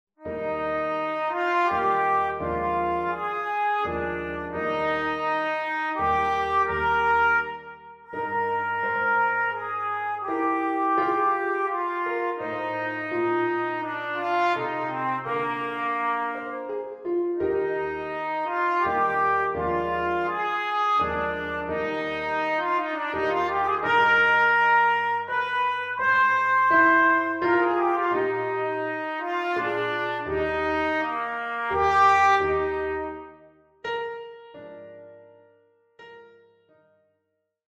transposed down a fourth
played on the Bb trumpet in F major